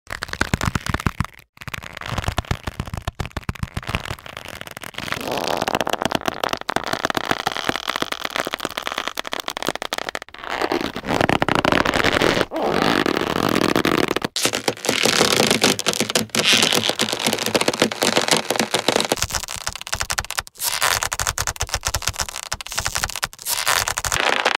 Звуки рвущейся веревки или каната, натягивание и связывания для монтажа видео в mp3
4. Скрипы и скрежет натянутой веревки